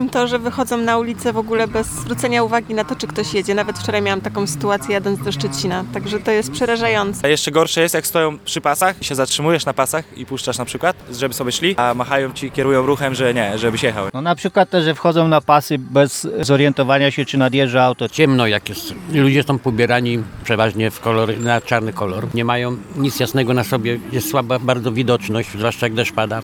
Zapytaliśmy kierowców o co mają największe pretensje do przechodniów.
kierowcy o pieszych(1).mp3